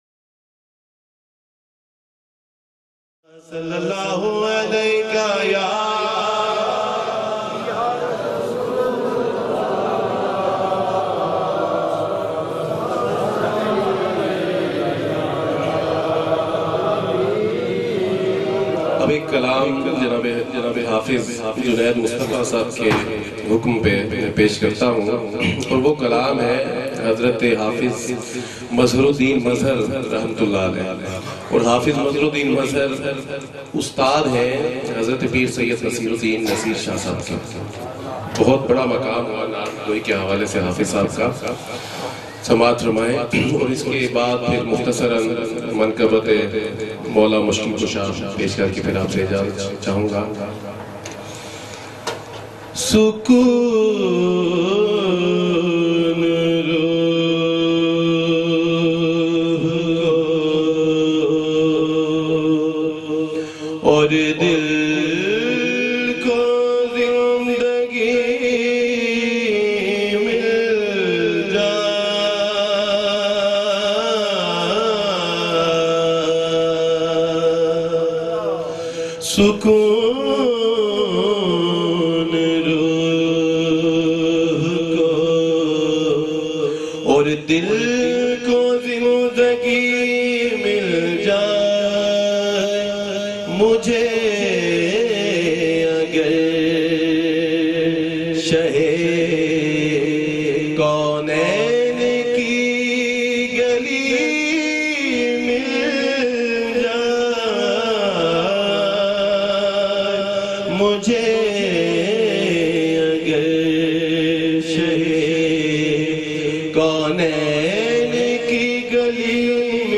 Lyrics